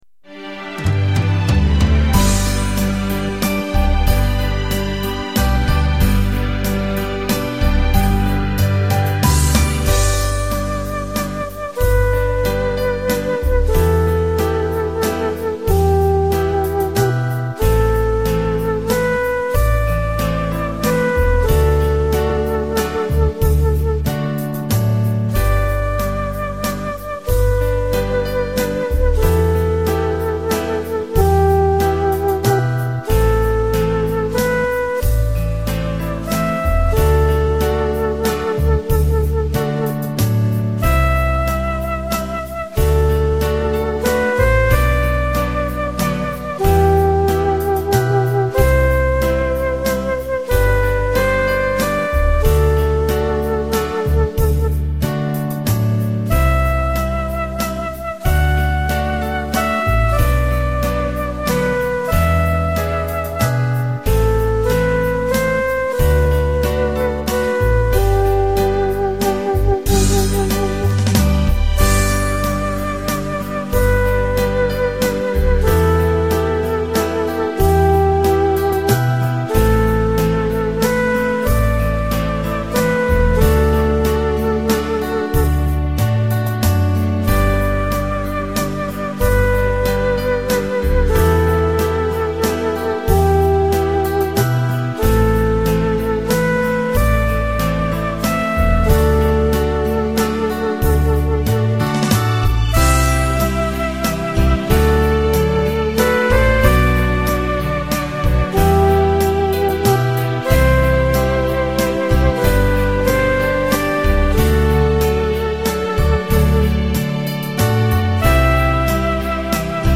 Walzer